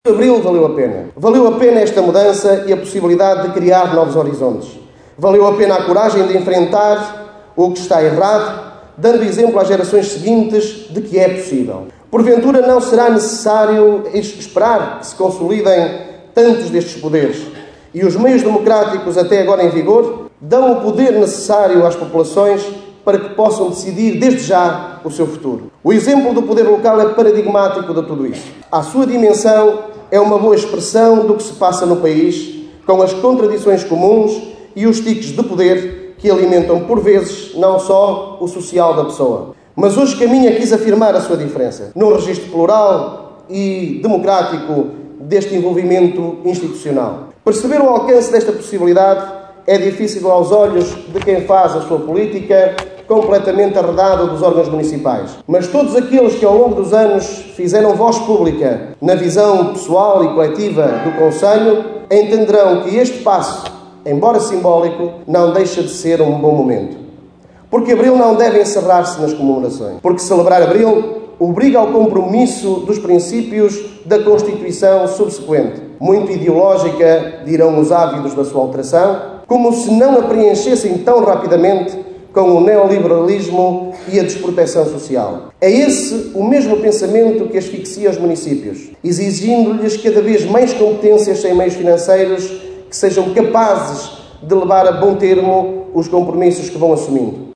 Foi com chuva que o 25 de Abril foi celebrado esta manhã em Caminha.